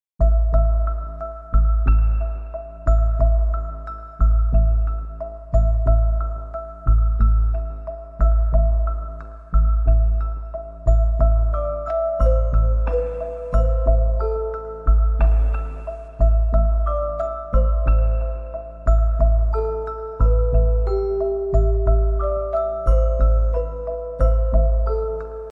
A police helicopter was seen sound effects free download By dailymailuk 260 Downloads 1 days ago 25 seconds dailymailuk Sound Effects About A police helicopter was seen Mp3 Sound Effect A police helicopter was seen flying at an extremely low altitude as it chased after a man on a 'stolen' scrambler bike. The helicopter appeared to be just feet above the ground as it shadowed the rider through fields north of St Helens.